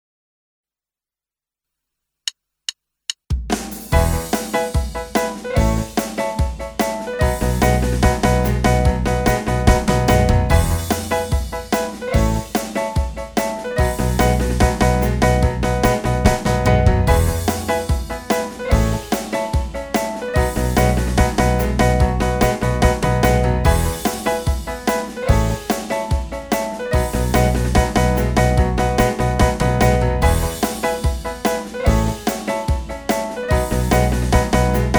Produkt zawiera utwór w wersji instrumentalnej oraz tekst.